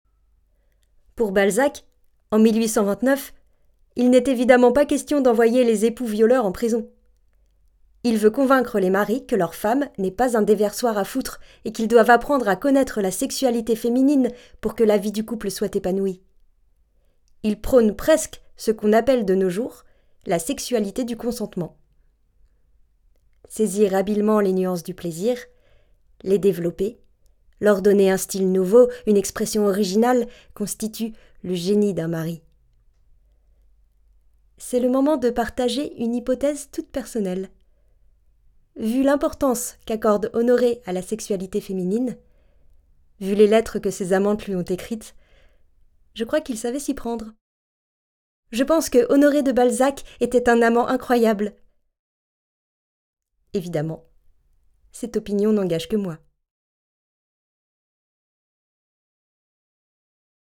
Narration Honoré et moi - Titiou Lecoq
14 - 34 ans - Soprano